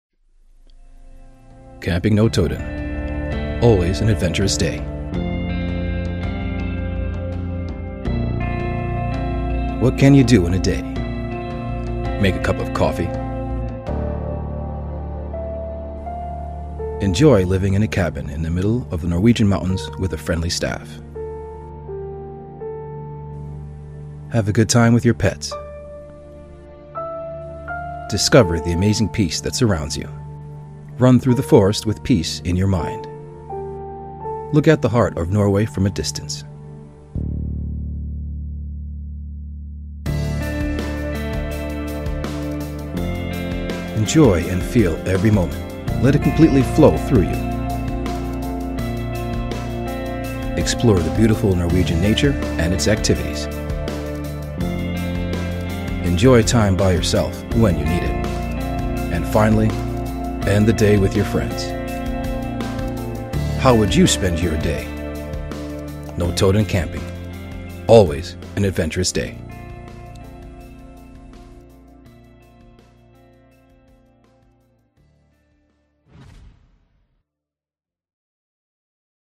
专题配音--磁性清爽